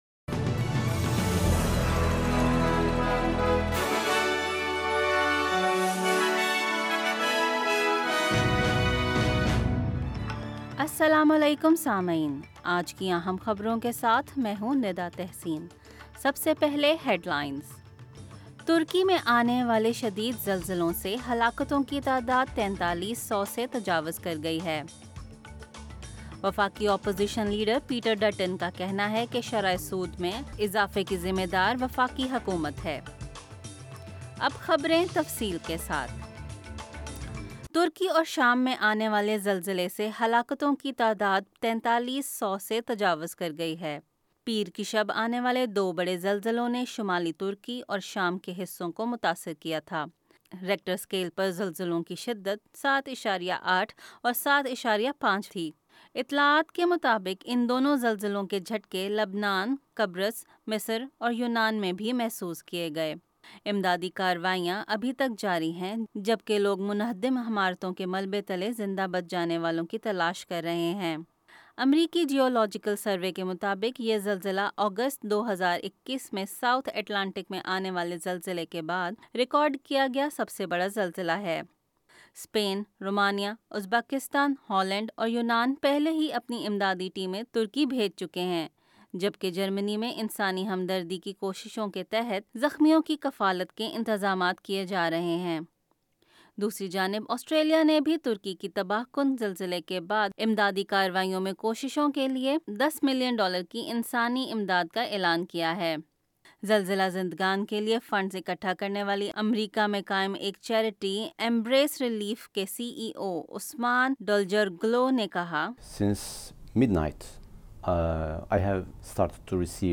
اردو خبریں منگل 7 فروری 2023